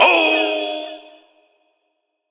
Vox 4 [ spongebob ].wav